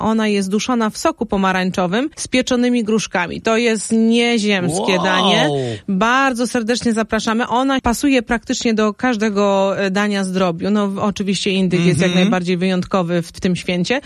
Jak powinien wyglądać świąteczny stół, mówi w naszym studiu